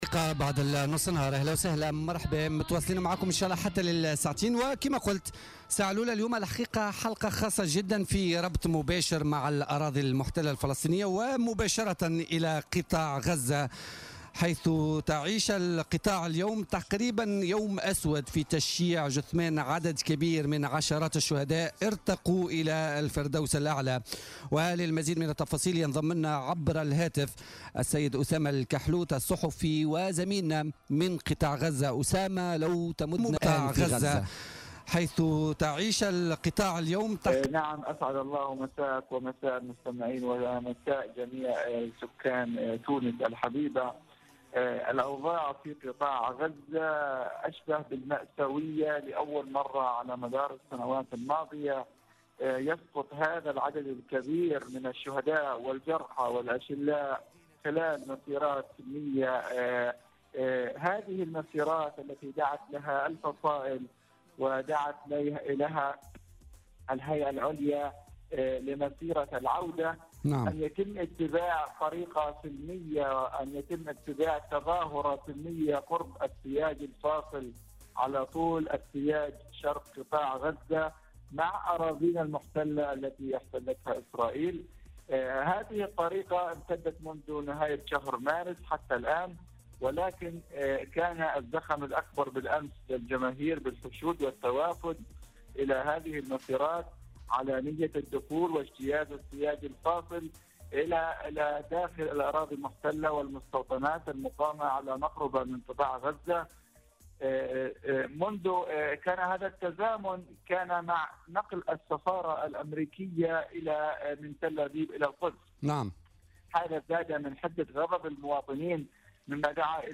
مداخلات من غزة